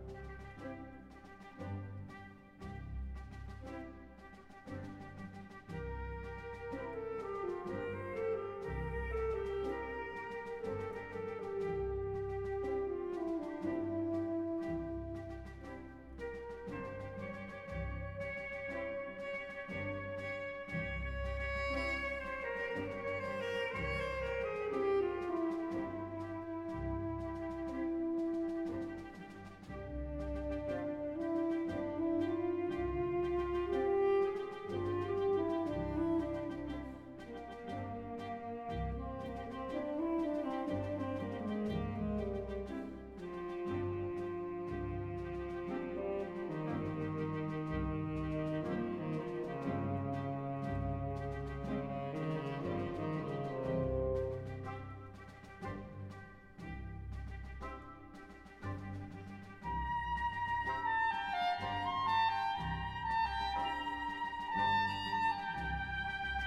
如果單純把 tPav2.wav 改名成 tPav02.wav，依上述播放，則 jplaymini 會先播放 tPav02.wav 再播放 tPav1.wav，完全相同的檔案之下，這下子 播放 tPav02.wav（ 就是原先的tPav2.wav）時就完全正常且正確，法國號定位在中間偏左處。